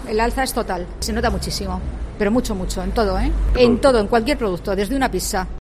Clienta Mercado San Blas